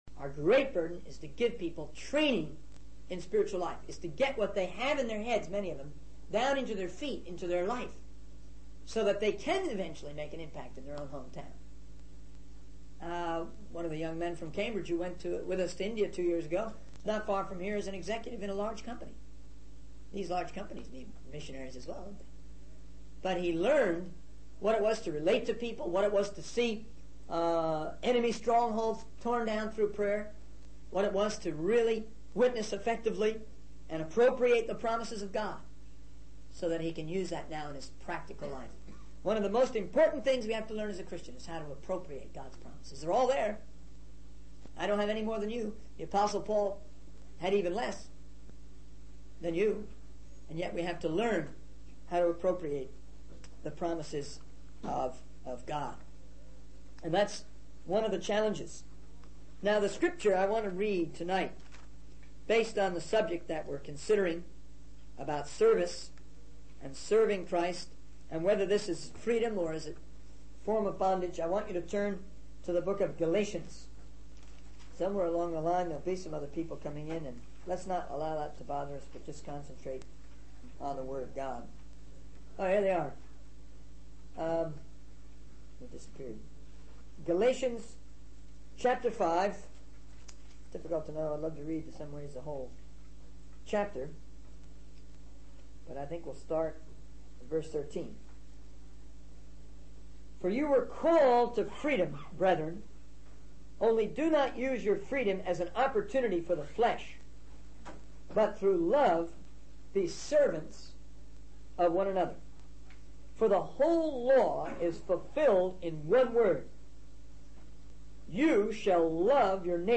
In this sermon, the speaker emphasizes the importance of training in spiritual life and applying the teachings of God in practical ways.